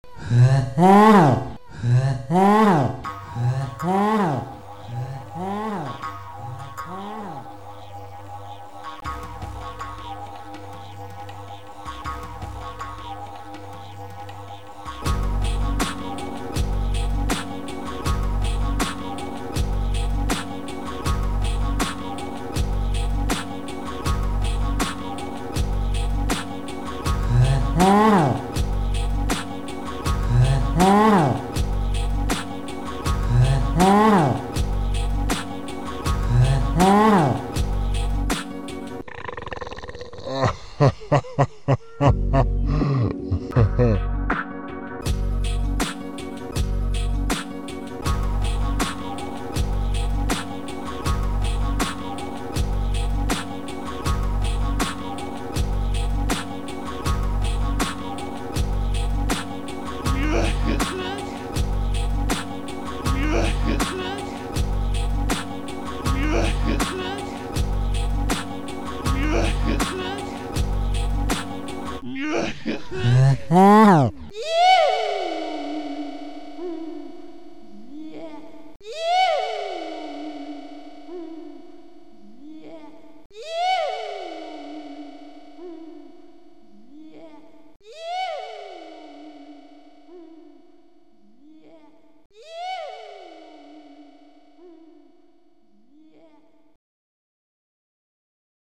Die Samples sind in billigster Produktion Hrhr aus irgendwelchen Liedern rausgezogen, die Drums sind z. B. aus "Never Be The Same" von Melanie C. Big Grin Lieder, die so enstanden sind, find ich immer noch am besten, so von der lustigen Erinnerung her.